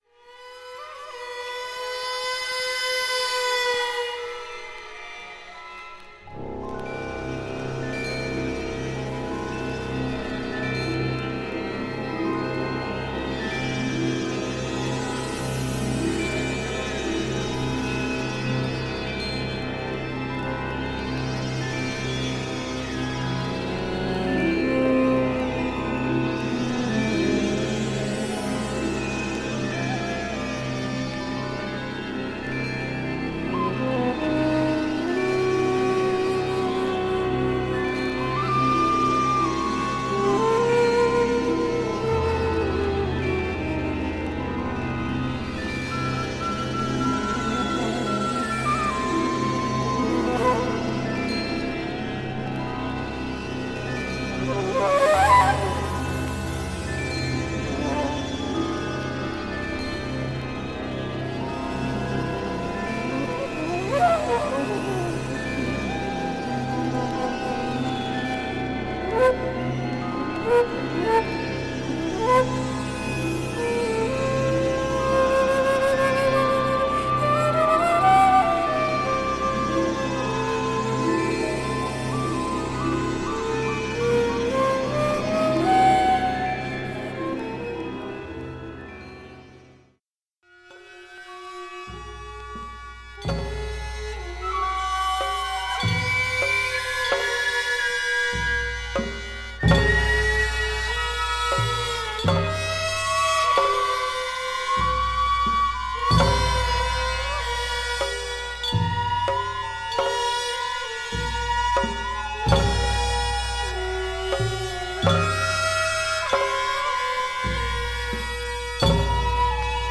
サウンドトラックです！！！